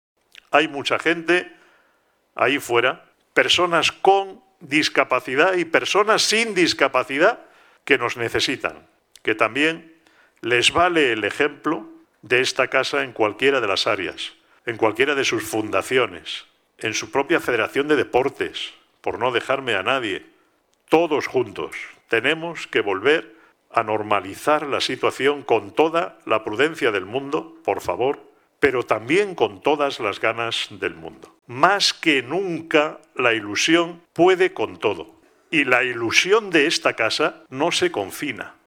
Un llamamiento que hizo el último Comité de Coordinación General (CCG) organizado por el Consejo General de la Organización los pasados 26 y 27 de noviembre, desde la sede de Fundación ONCE, ante un restringido grupo de asistentes presenciales debido al Covid, pero que conectó online a casi 160 personas de todos los puntos de la geografía española.